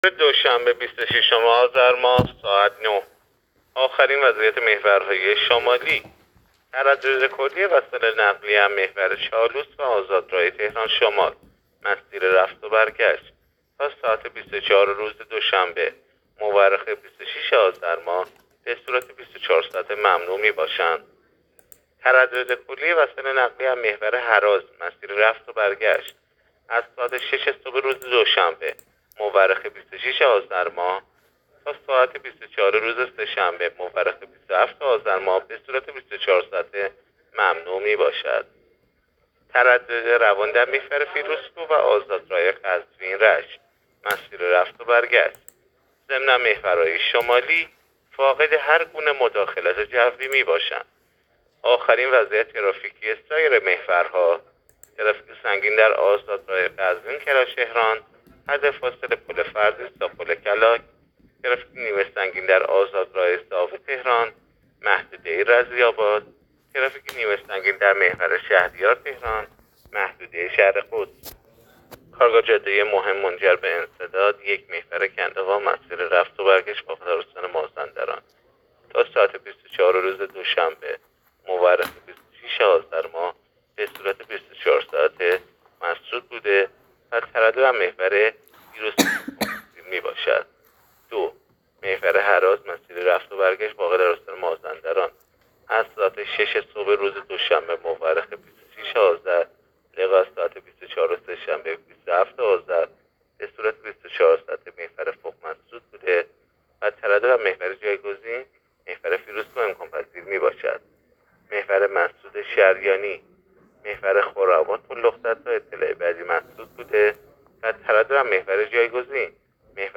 گزارش رادیو اینترنتی از آخرین وضعیت ترافیکی جاده‌ها تا ساعت ۹ بیست‌وچهارم آذر؛